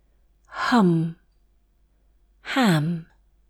The Spanish centre-back, unrounded vowel /a/ may be used for both /ʌ/ and /æ/ in British English.